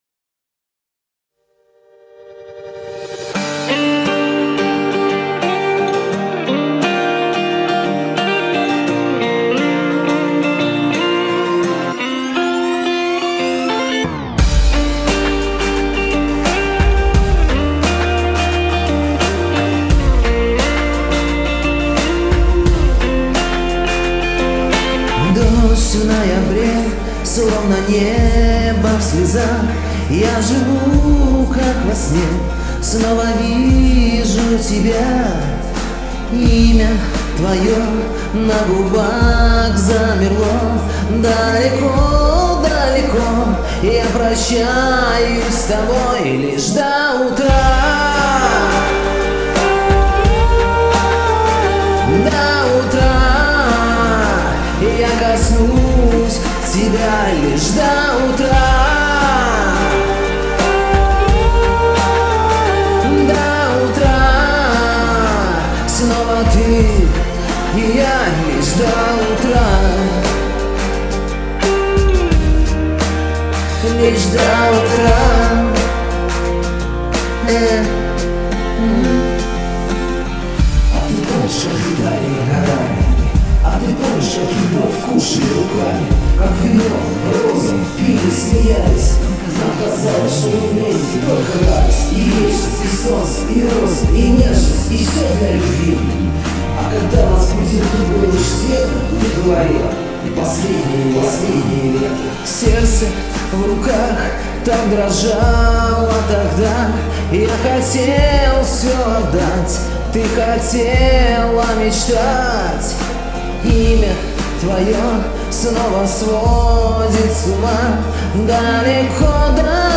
и рэп... ааааайййй... класс!!!!)))))))